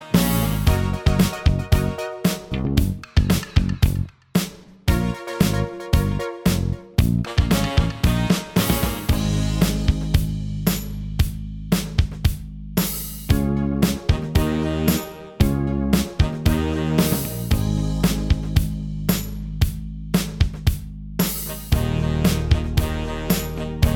Minus Guitars Pop (1980s) 4:02 Buy £1.50